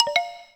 GameSave.wav